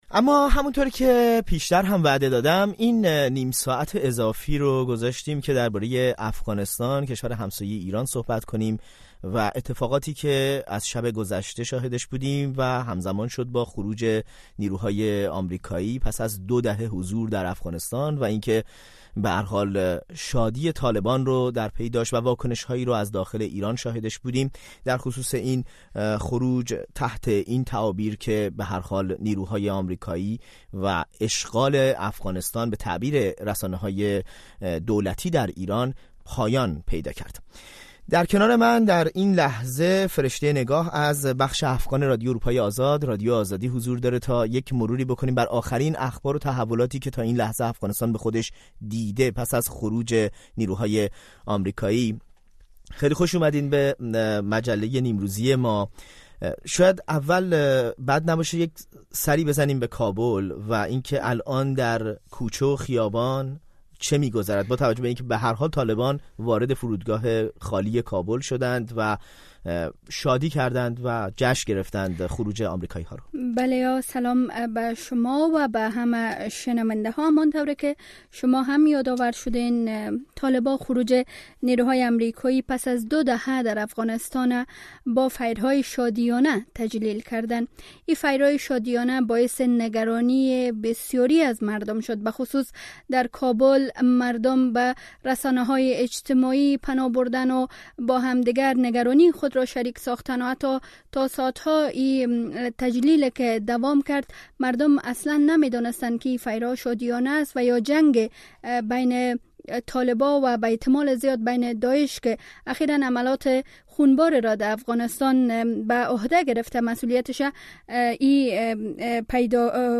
میزگردی